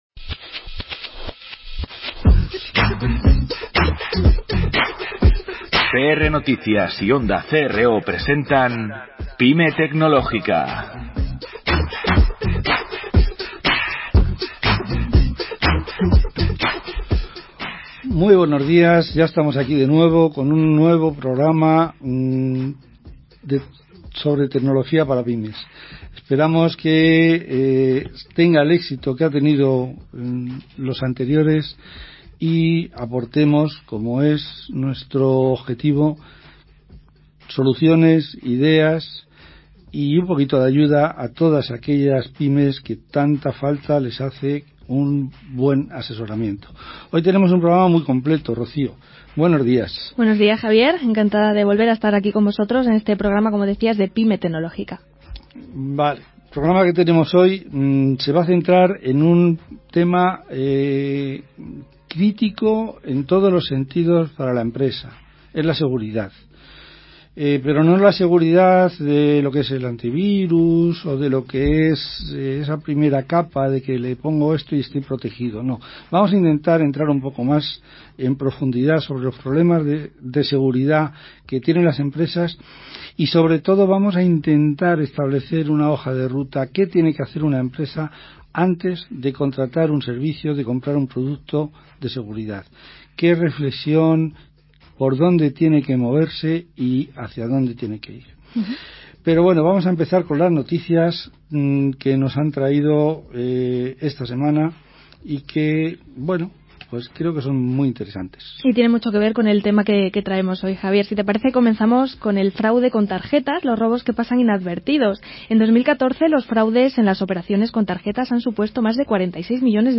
En Pyme Tecnológica, hemos podido debatir sobre la seguridad en las empresas, sobre todo en las pymes, con expertos de INCIBE, la Guardia Civil o Aenor.